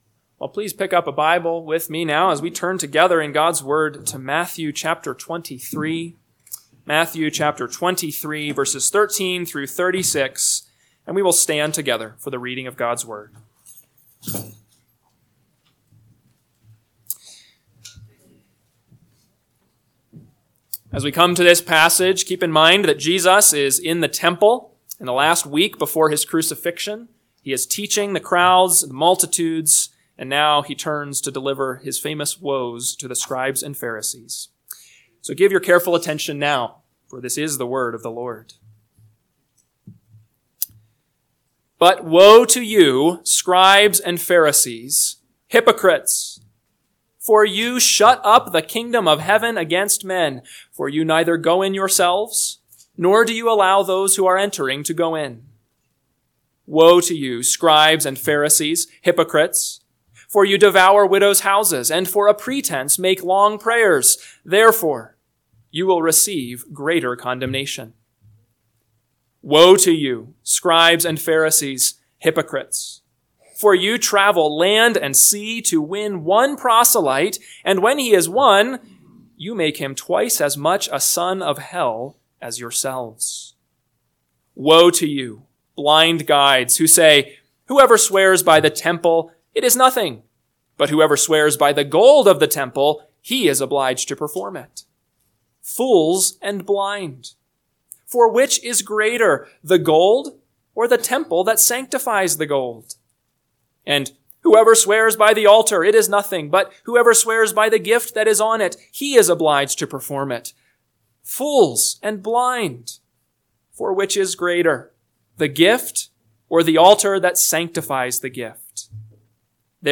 AM Sermon – 11/17/2024 – Matthew 23:13-36 – Northwoods Sermons